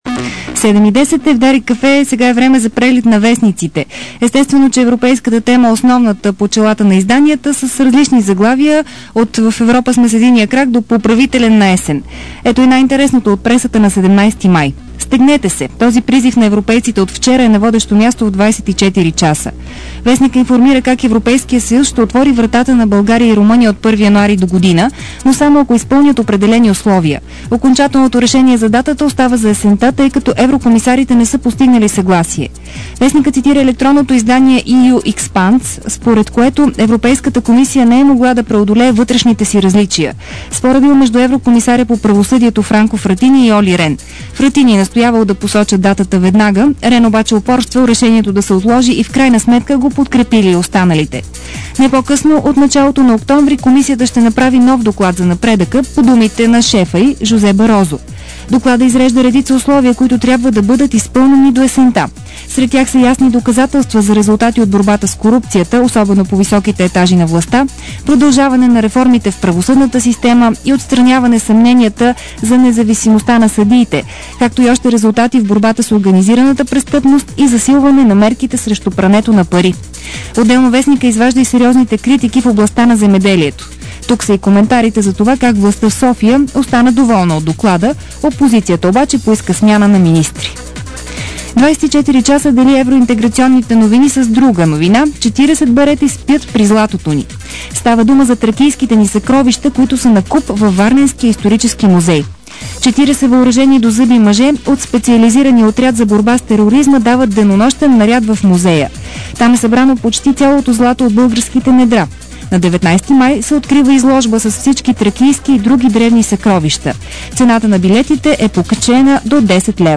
DarikNews audio: Преглед на печата